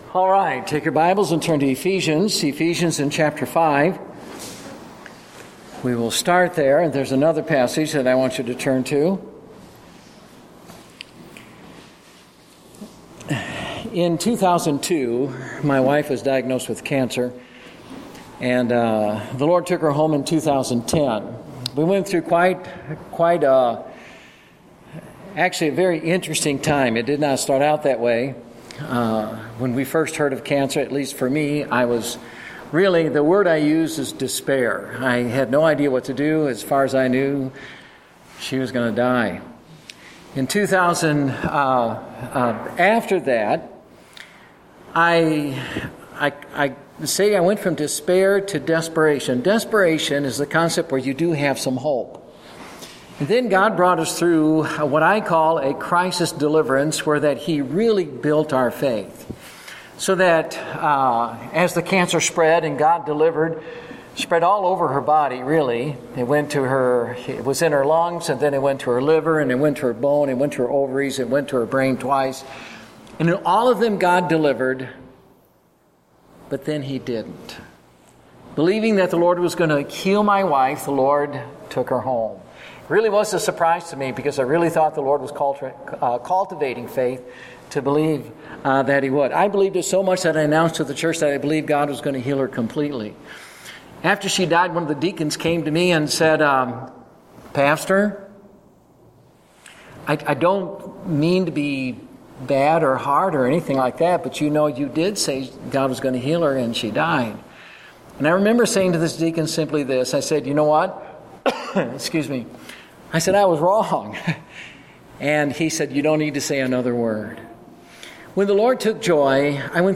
Date: August 22, 2014 (Family Camp)